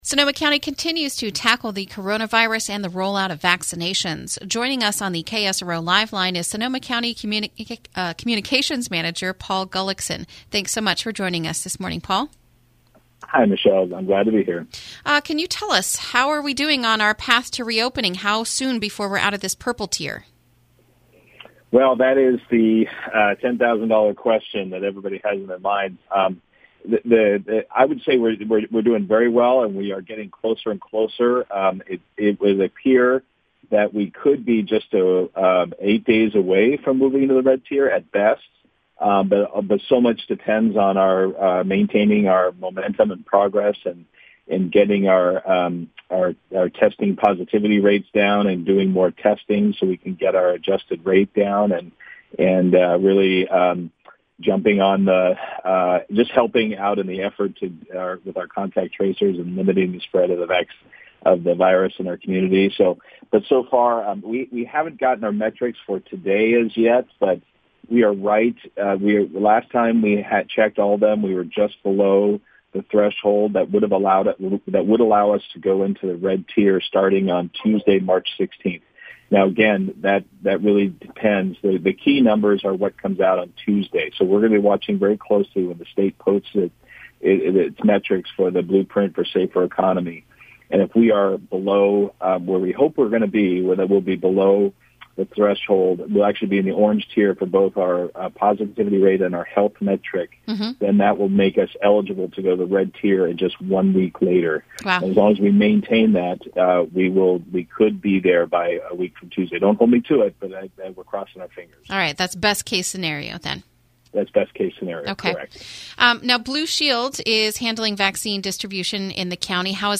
INTERVIEW: Sonoma County May Move Into Less Restrictive Tier Soon